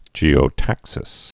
(jēō-tăksĭs)